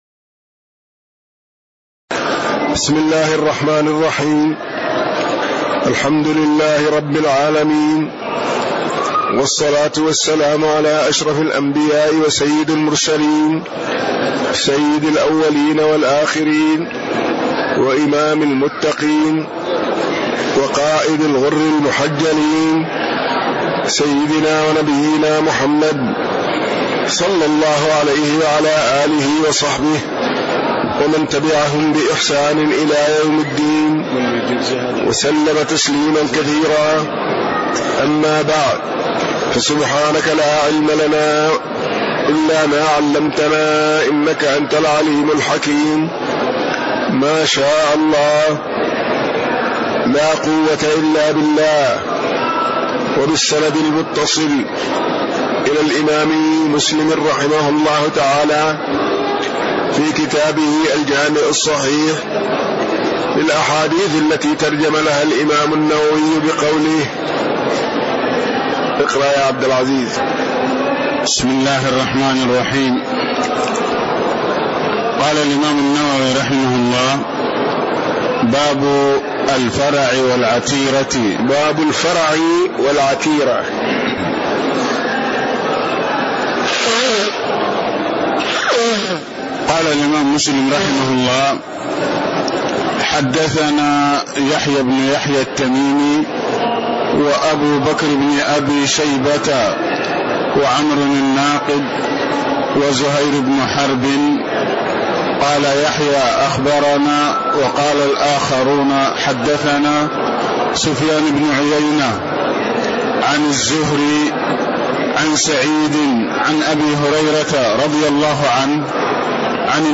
تاريخ النشر ٤ جمادى الآخرة ١٤٣٦ هـ المكان: المسجد النبوي الشيخ